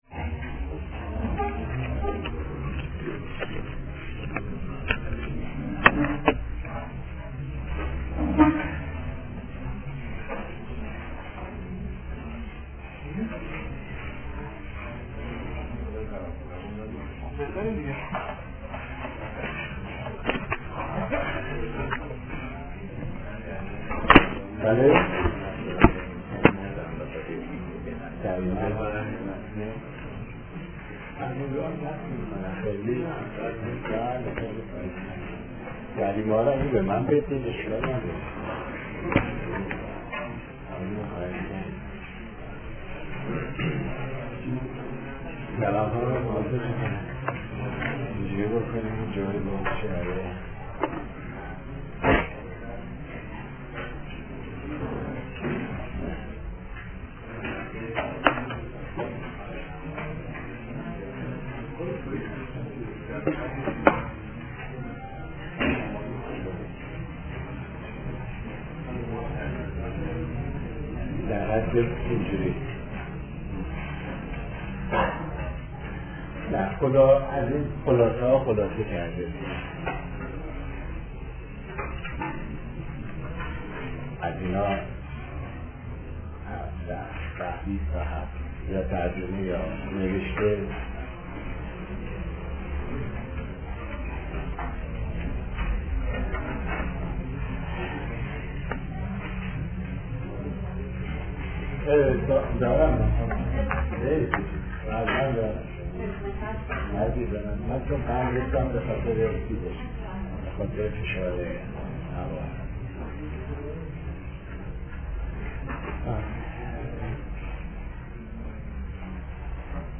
فرهنگ امروز: فایل حاضر قسمت سوم درس‌گفتار های ماکیاوللی است که «سیدجواد طباطبایی» سال‌ها پیش آن را تدریس کرده است.